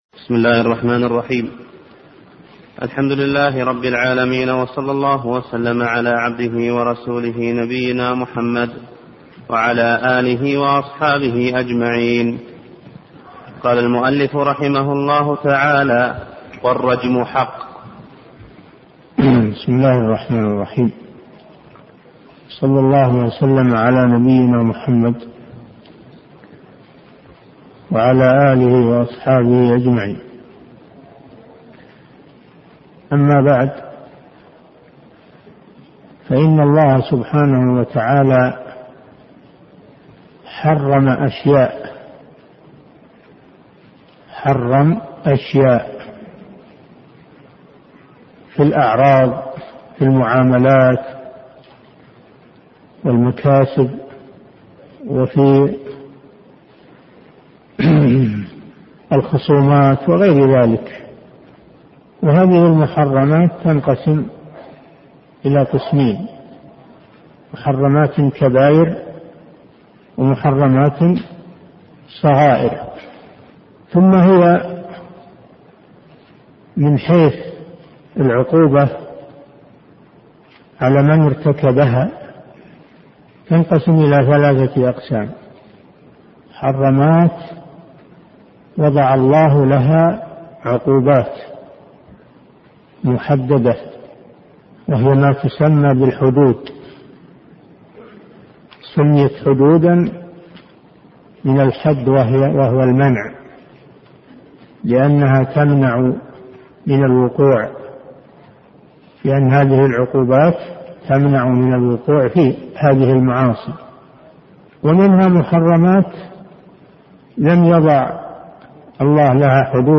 أرشيف الإسلام - ~ أرشيف صوتي لدروس وخطب ومحاضرات الشيخ صالح بن فوزان الفوزان